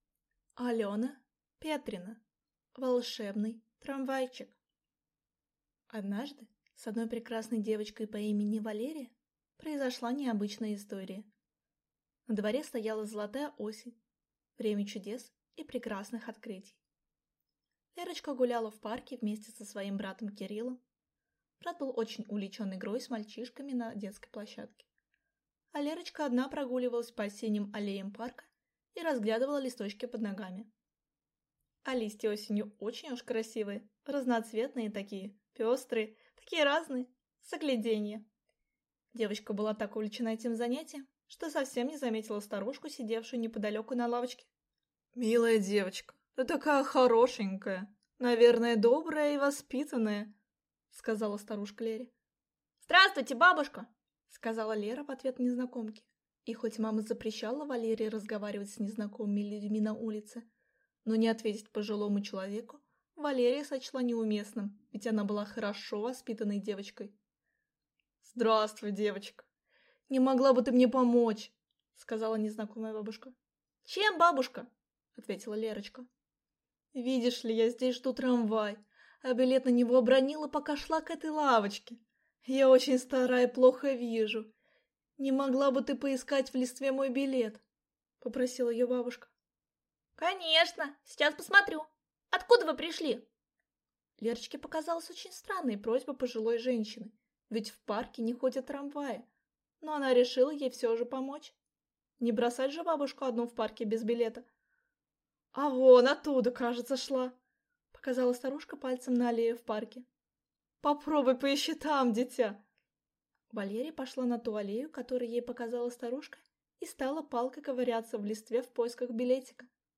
Аудиокнига Волшебный трамвайчик | Библиотека аудиокниг